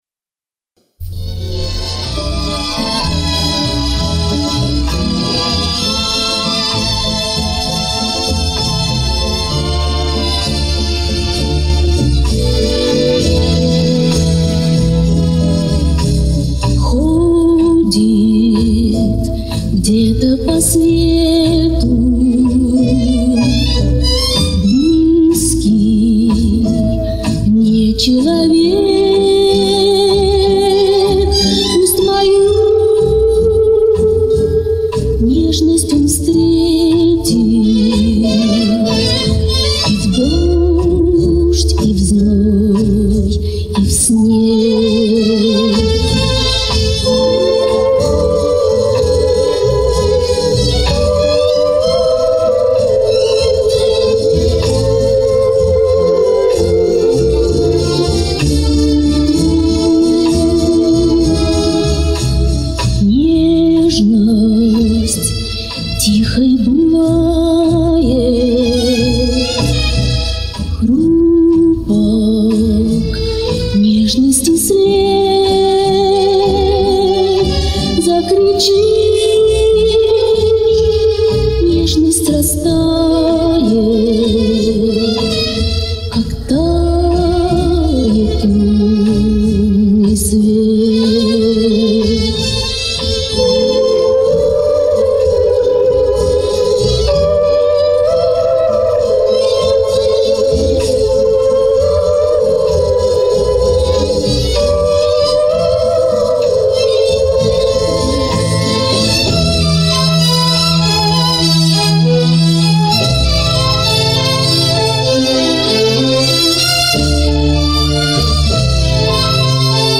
Качество получше.